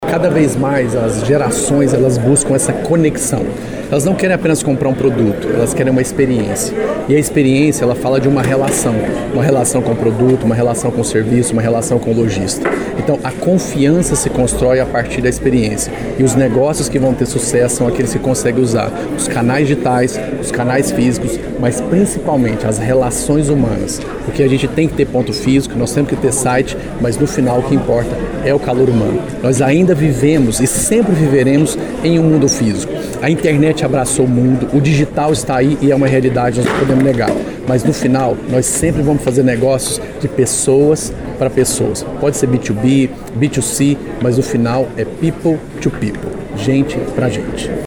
Palestra “A Revolução Tecnológica do Mercado” fecha Semana Empresarial 2023 em Pará de Minas
Para fechar mais uma edição da tradicional Semana Empresarial 2023, em Pará de Minas, a Ascipam e o Sicoob Ascicred promoveram na noite da última terça-feira (18), no Shopping Fabrika Mall, mais uma importante palestra para empresários e associados. O evento contou mais uma vez com um grande público.
Os dois dias da Semana Empresarial 2023 foram realizados em uma das salas do cinema Cine Ritz do Shopping Fabrika Mall.